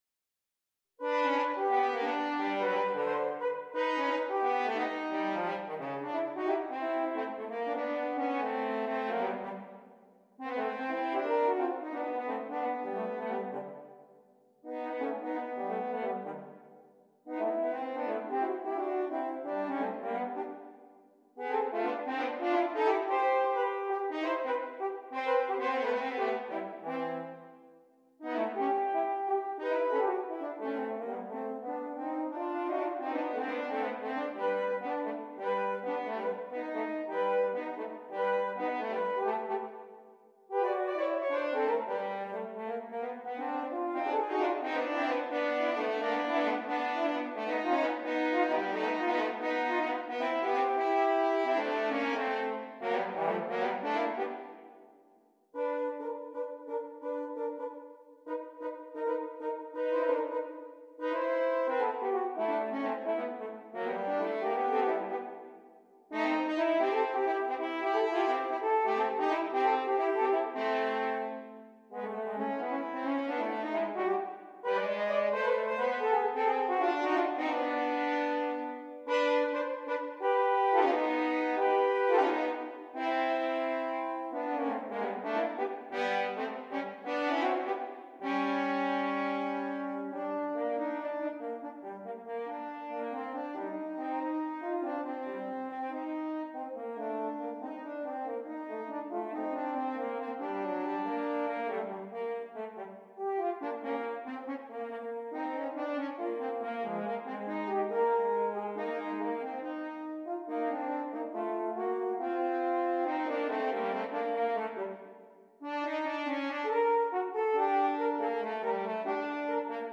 2 F Horns
Difficulty: Medium-Difficult Order Code